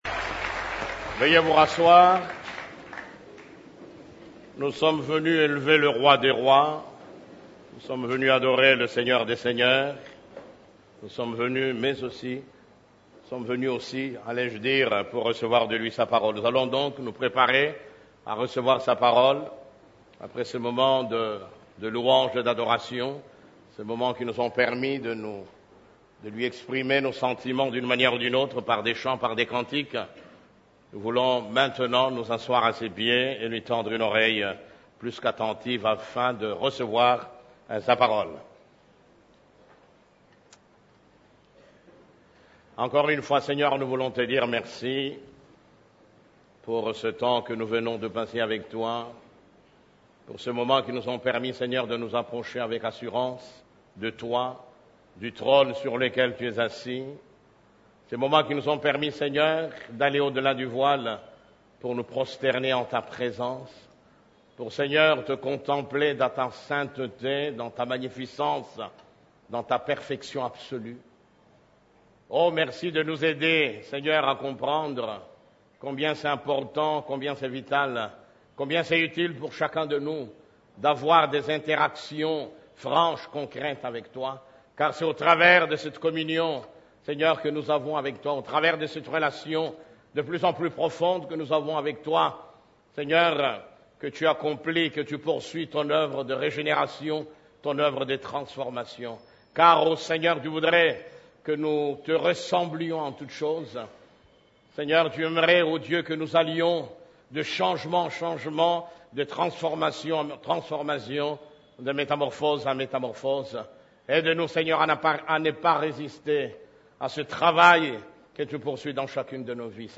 Culte du Dimanche